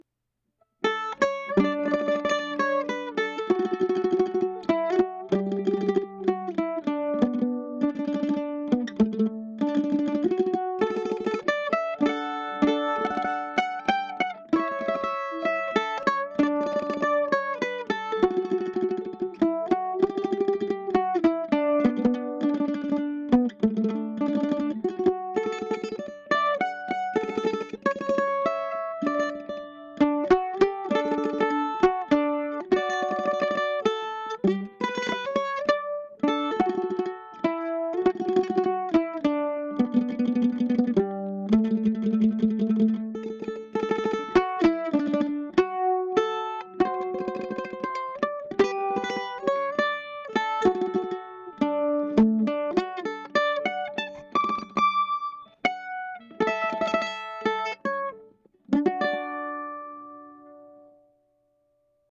Here are sound smples recorded by the terrific mandolinist